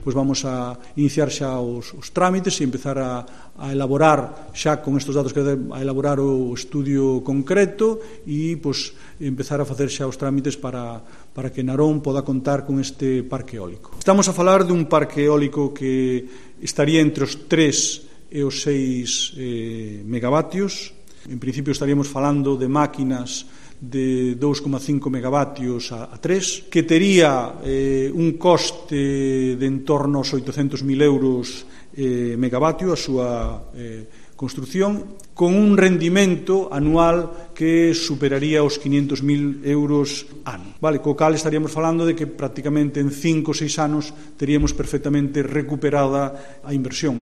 El concejal naronés, Pablo Villamar, anunció el proceso esta mañana en rueda de prensa.